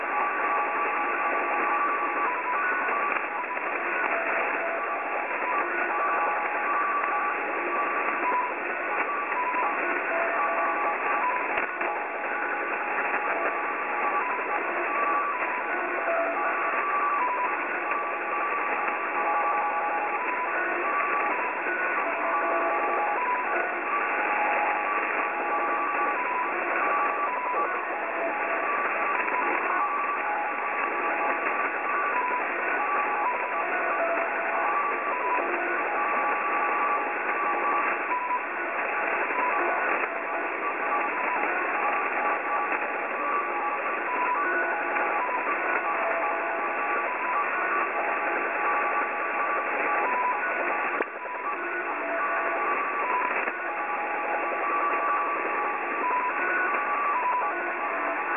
Recieved on 8313khz